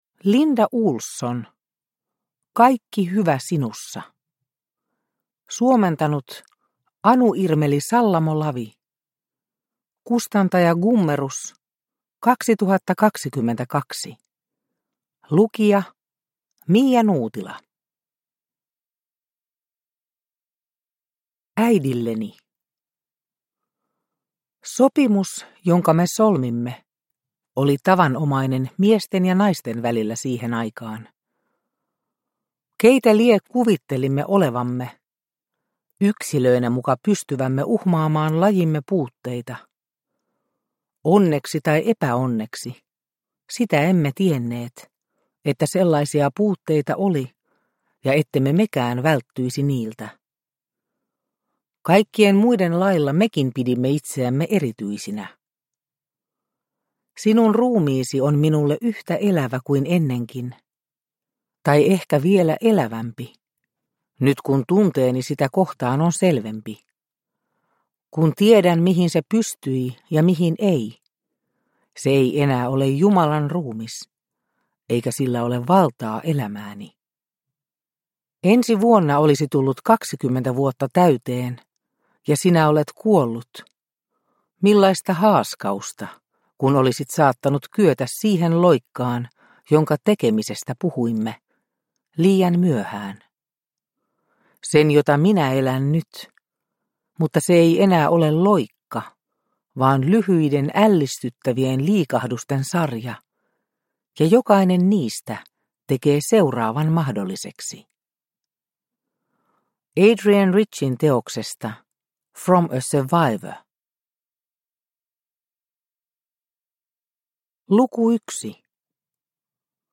Kaikki hyvä sinussa – Ljudbok – Laddas ner